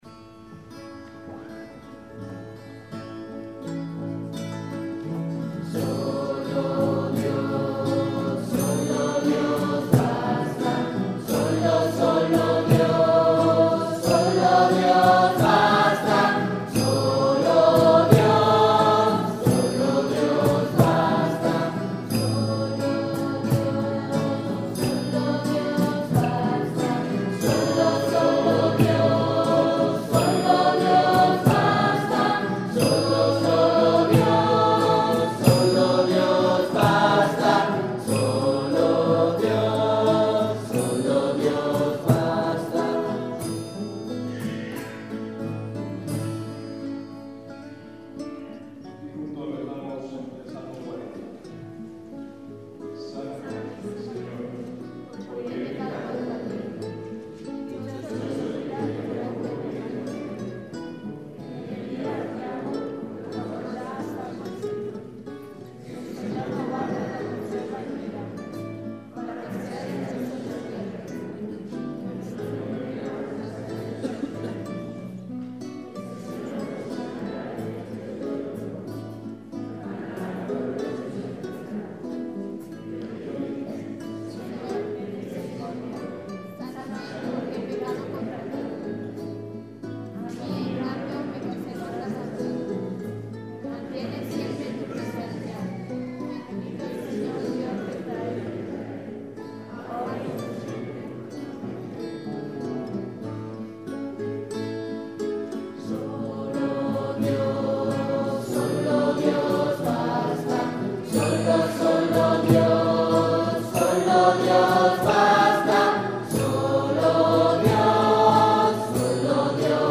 Canto: